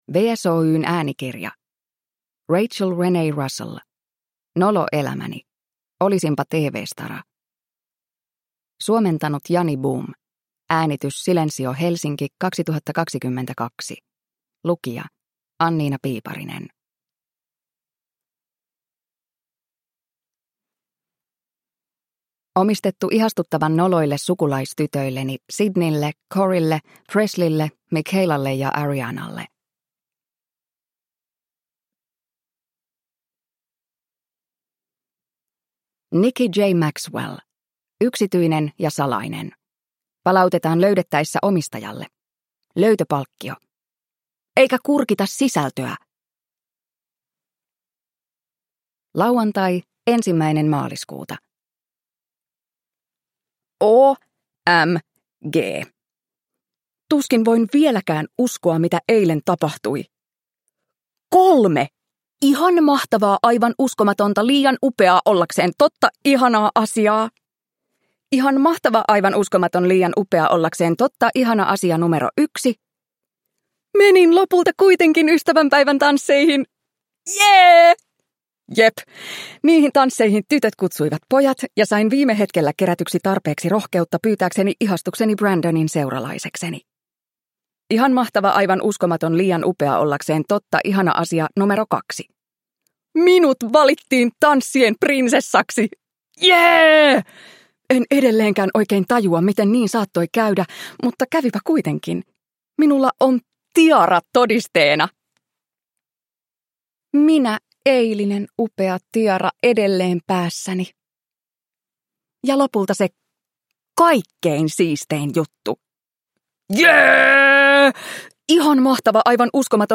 Nolo elämäni: Olisinpa tv-stara – Ljudbok – Laddas ner